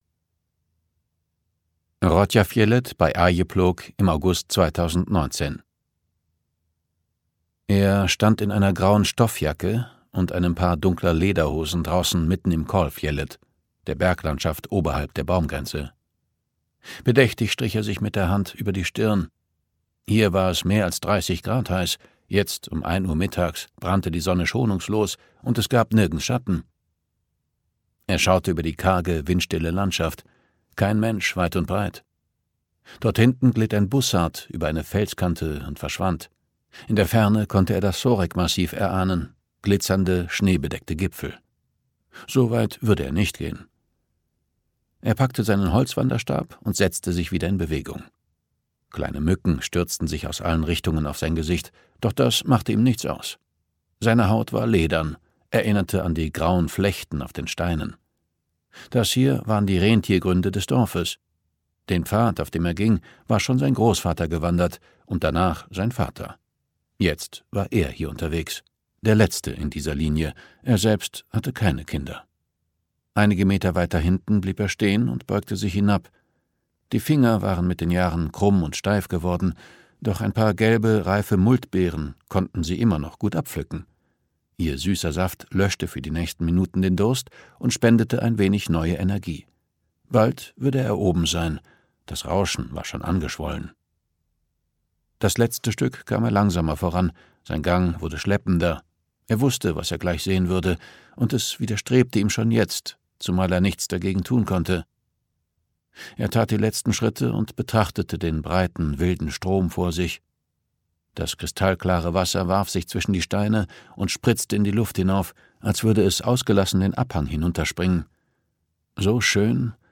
Hörbuch Kaltes Gold von Rolf Börjlind und Cilla Börjlind.
Ukázka z knihy